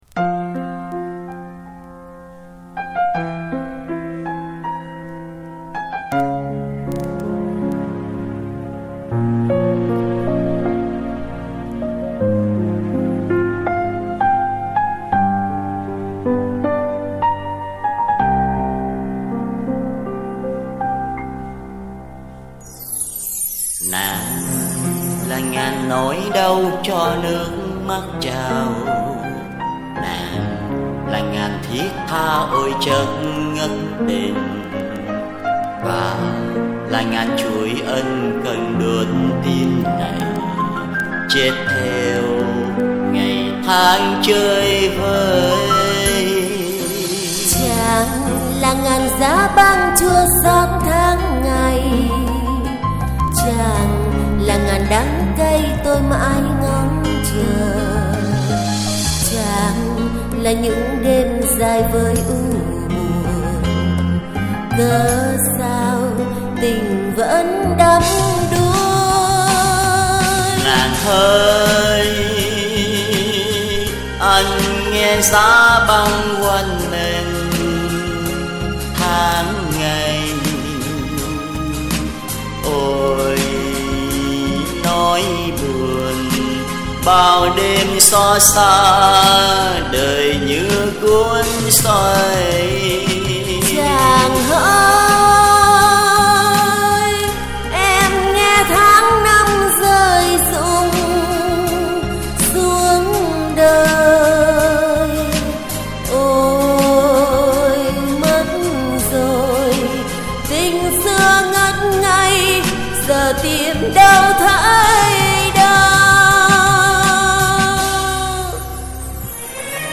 Phiên bản giọng Nữ bị cảm .... giọng hát hơi khác chút xíu 😅